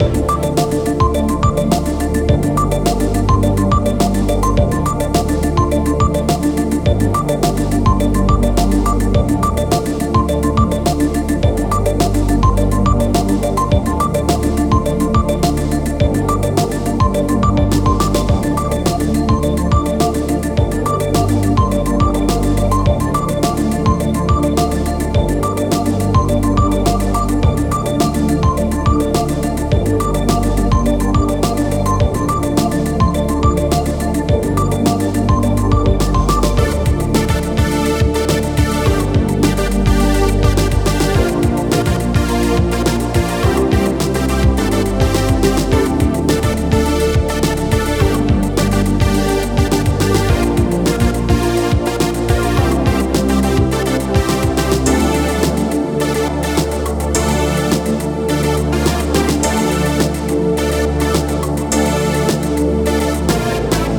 Stage select music for puzzle game.